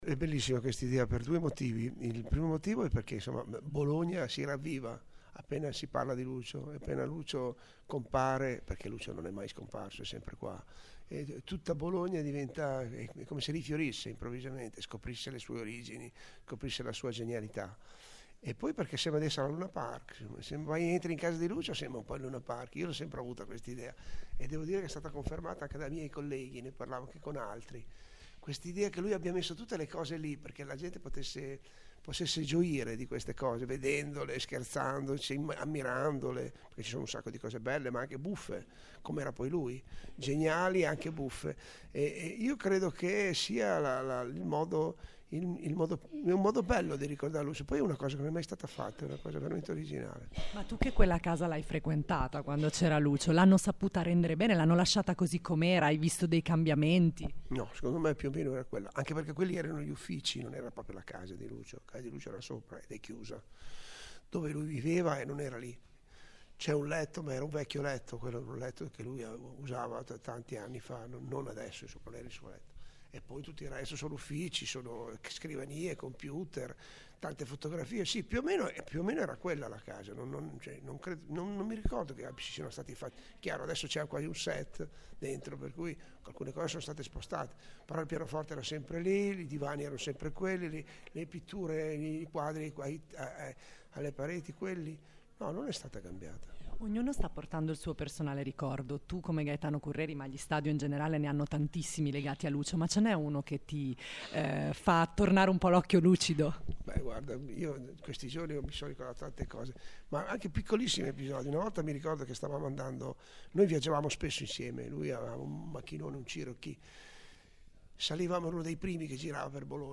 Le interviste in occasione di A casa di Lucio
Gaetano-Curreri-ricorda-Lucio.mp3